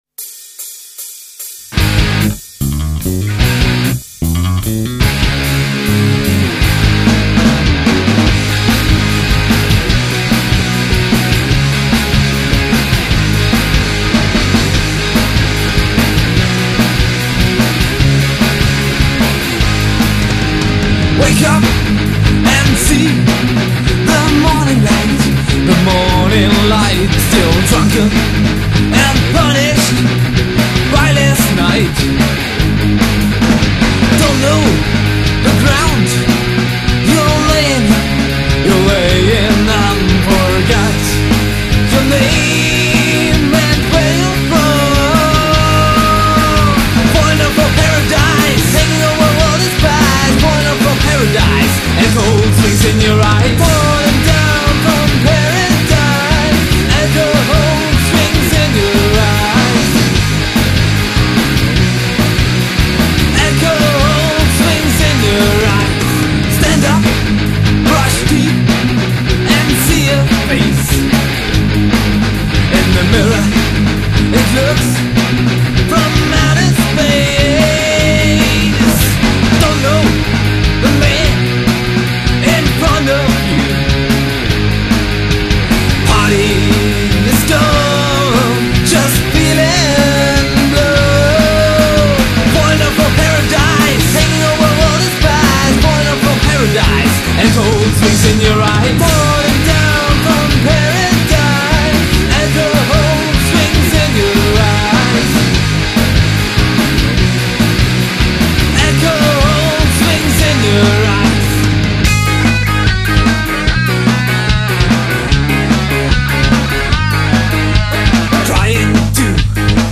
Gesang & Gitarre
Bass